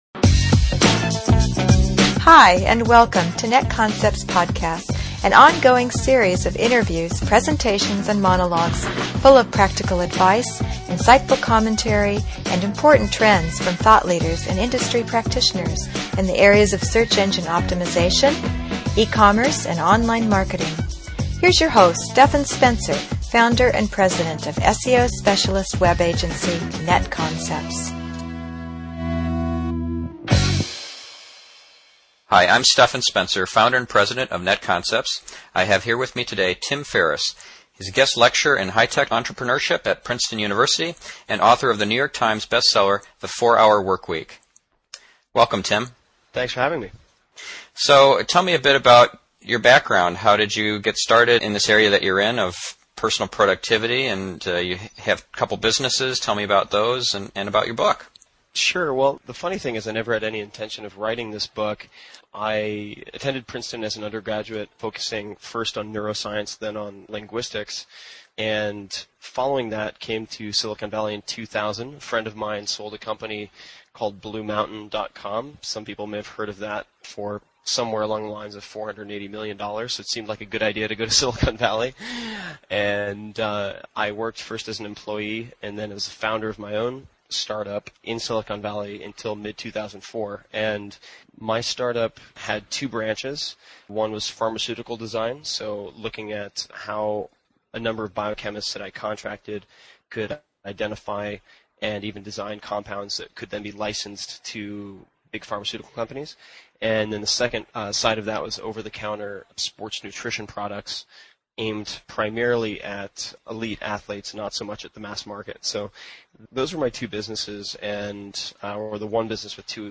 tim-ferriss-interview.mp3